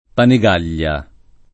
[ pani g# l’l’a ]